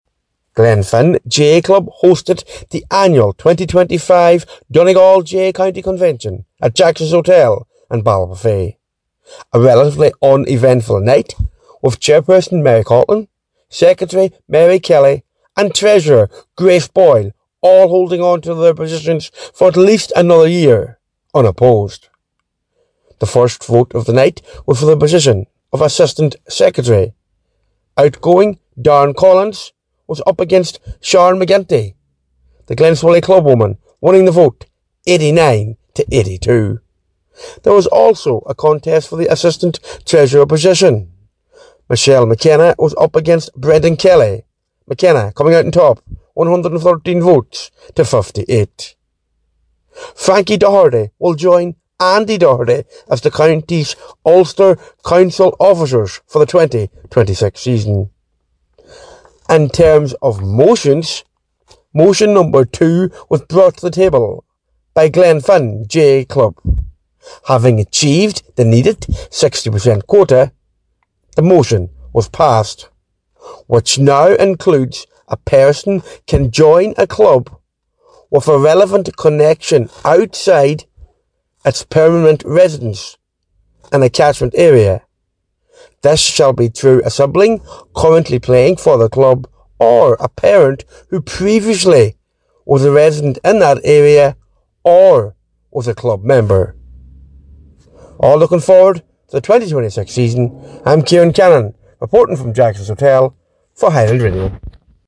has the details from Jacksons Hotel for Highland Radio Sport….